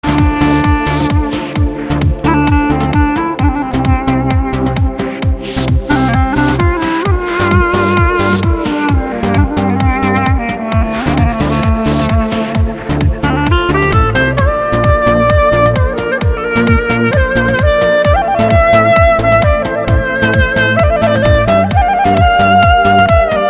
• Качество: 192, Stereo
без слов
инструментальные
восточные
духовые
дудук
Дудук - духовой музыкальный инструмент.